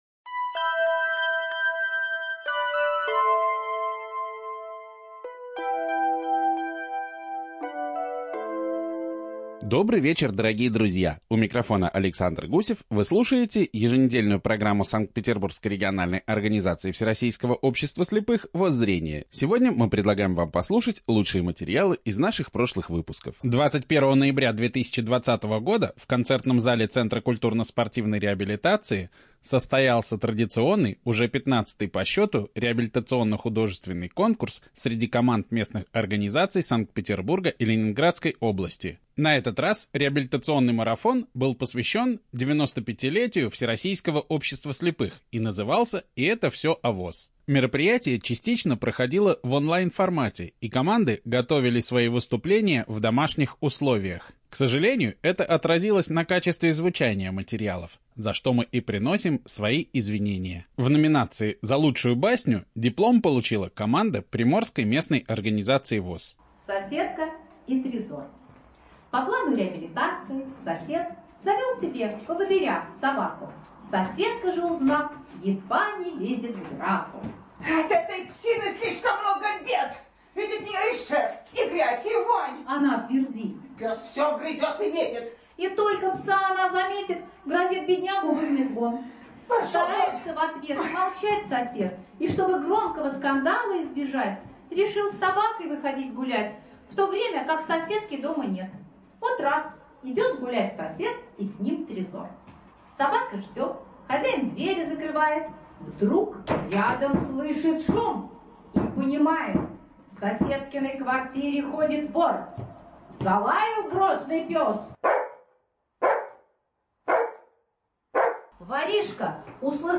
ЖанрРадиопрограммы